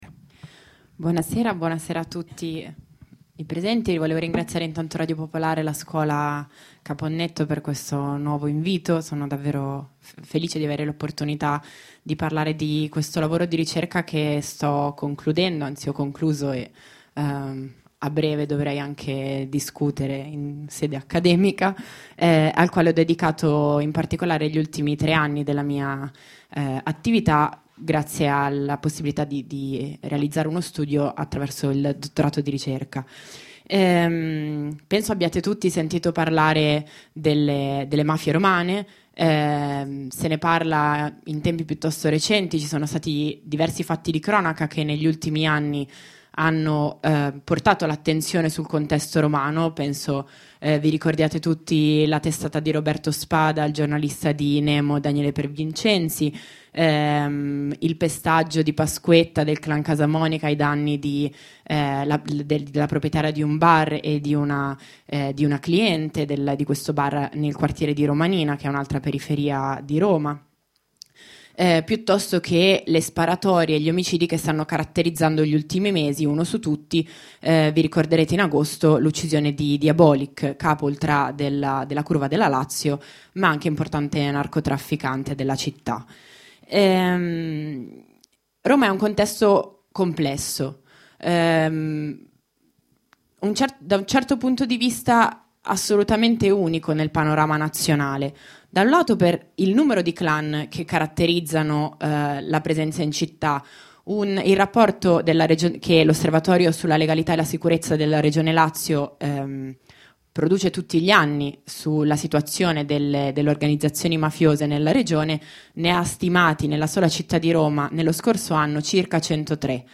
STORIE E RITRATTI DI DONNE E UOMINI CONTRO MAFIA E CORRUZIONE Terza lezione: In nome del futuro – Scuola Antonino Caponnetto